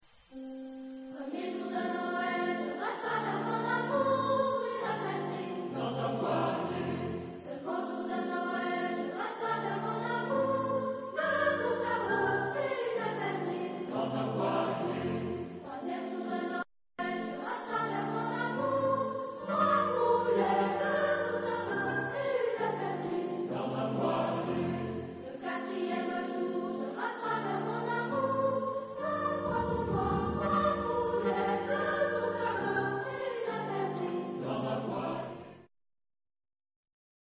Genre-Style-Forme : Profane ; noël
Caractère de la pièce : joyeux ; vivant
Tonalité : fa majeur